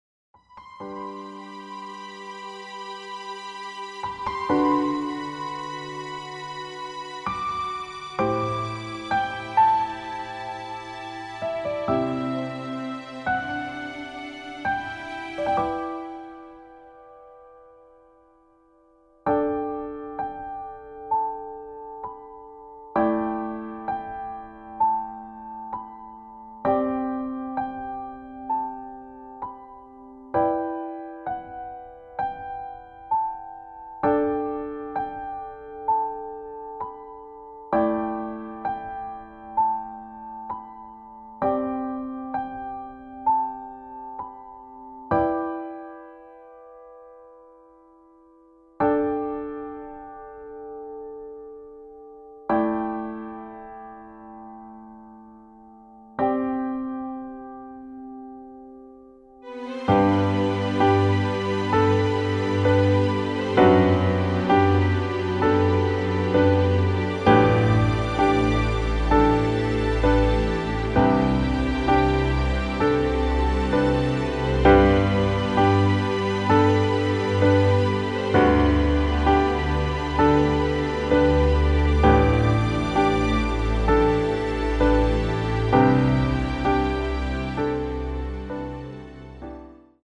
• Tonart:  D Dur, E Dur, G Dur
• Art: Klavier Streicher Version
• Das Instrumental beinhaltet keine Leadstimme
• Alle unsere DEMOS sind mit einem Fade-In/Out.
Klavier / Streicher